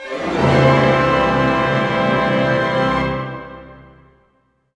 Index of /90_sSampleCDs/Roland LCDP08 Symphony Orchestra/ORC_Orch Gliss/ORC_Major Gliss